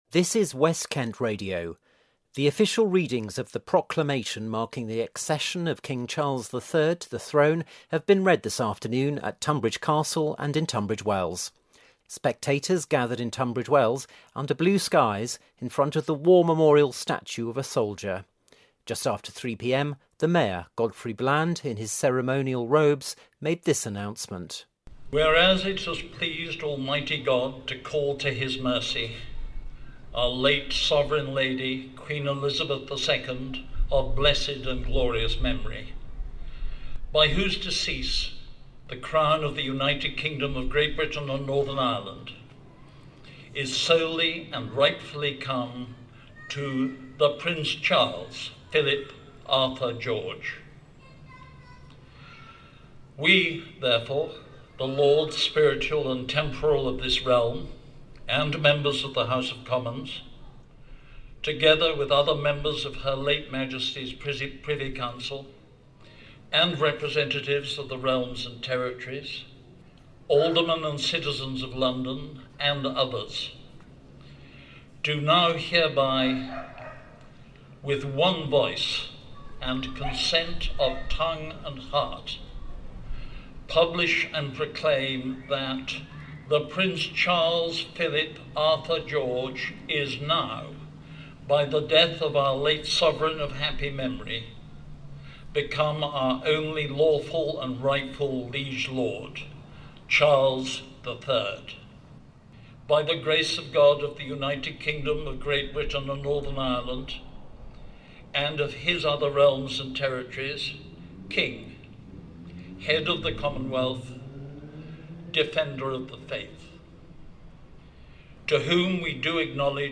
Tunbridge Wells Proclamation Reading
Spectators gathered in Tunbridge Wells under blue skies in front of the war memorial statue of a soldier. Just after 3pm, the mayor, Godfrey Bland, in his ceremonial robes made this announcement.